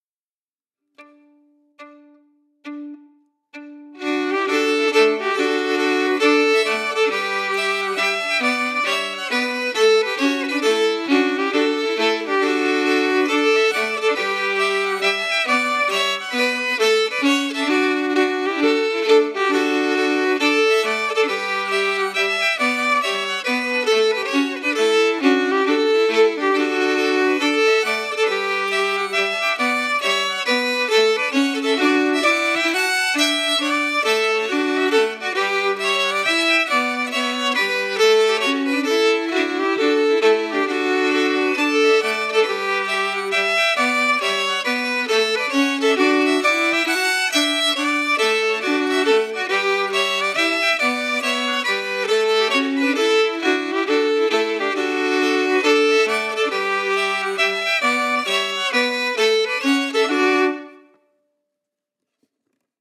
Key: D-major
Form: Hornpipe
Harmony emphasis
Genre/Style: Irish hornpipe